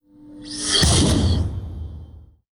GameDevTVTutFPS/SciFiDoor 9009_14_2.wav at main